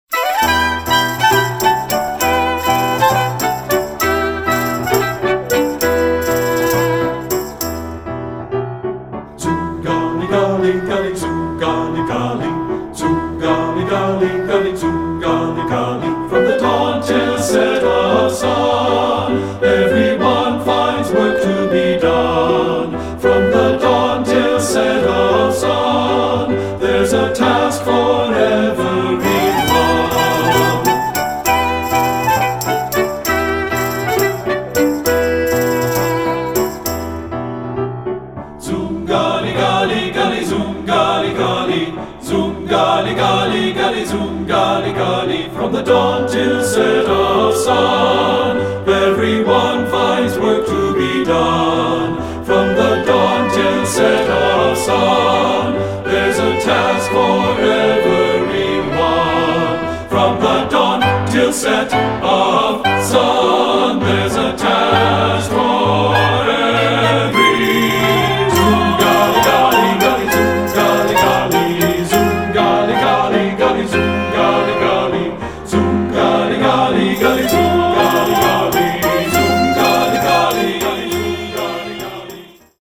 Choral Male Chorus Multicultural
Israeli Folk Song
TBB